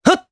Evan-Vox_Attack2_jp_b.wav